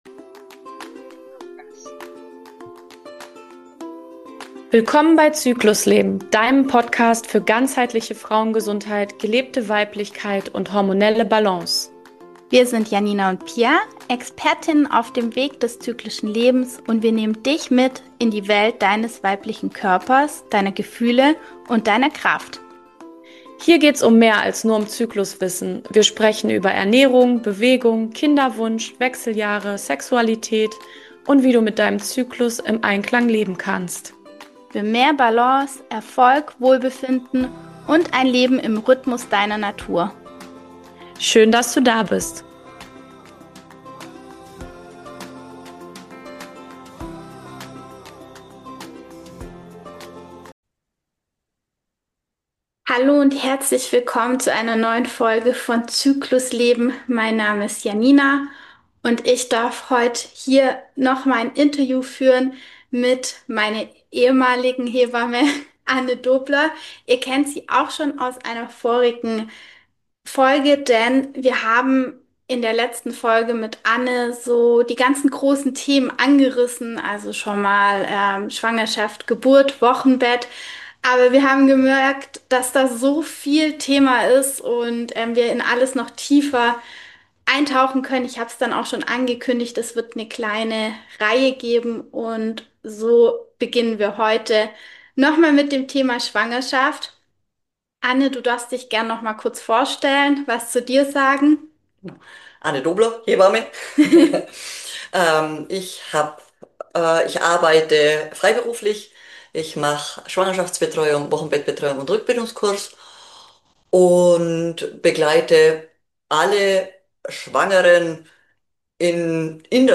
Schwangerschaft im Gespräch mit einer Hebamme ~ Zyklusleben Podcast